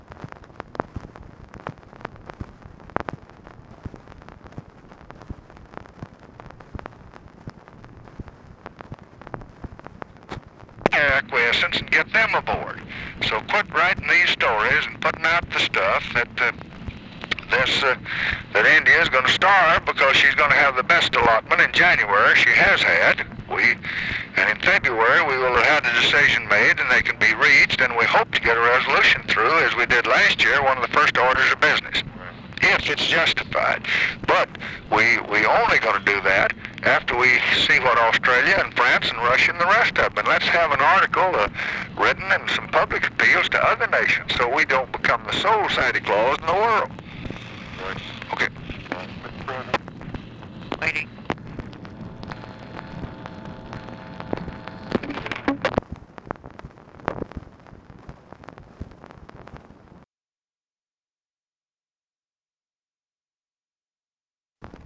Conversation with NICHOLAS KATZENBACH, December 8, 1966
Secret White House Tapes